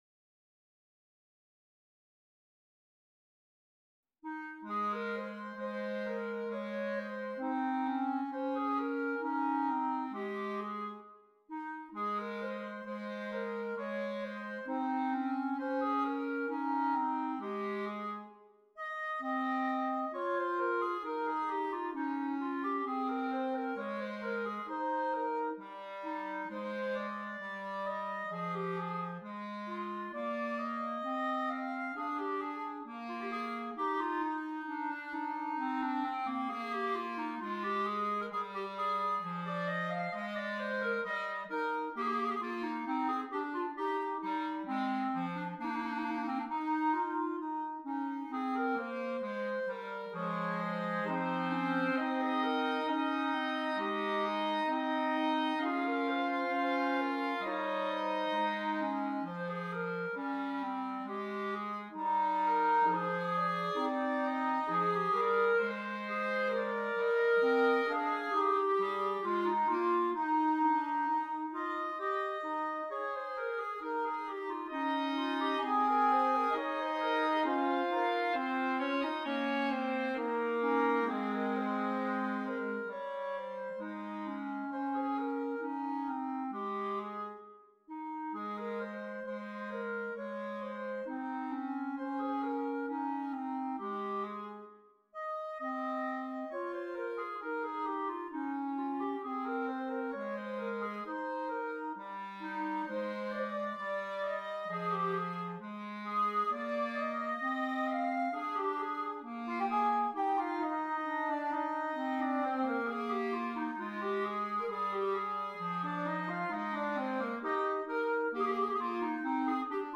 6 Clarinets